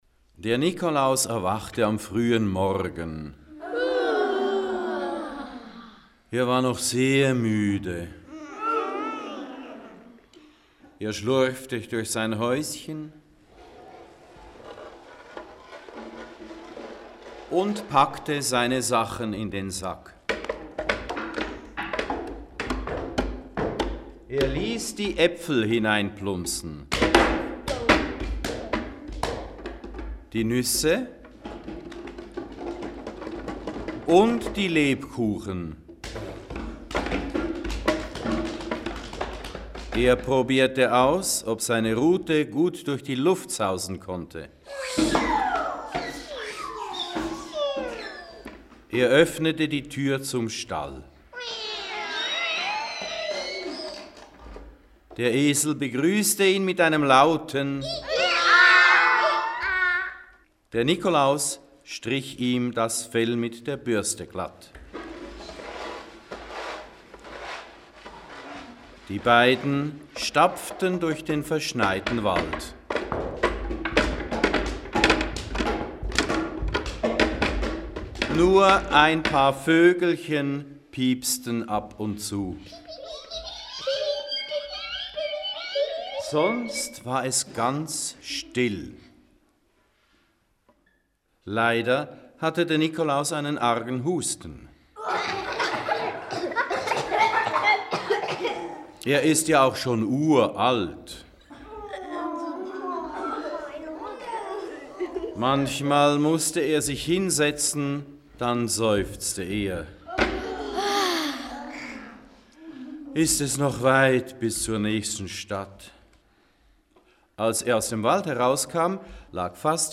Ger�usch-Geschichten
Eine Samichlausgeschichte Ger�usche: Kinder des Neeracher Theaterkurses
Samichlaus_Geraeuschgeschichte_Dez_08.mp3